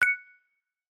newNotification.mp3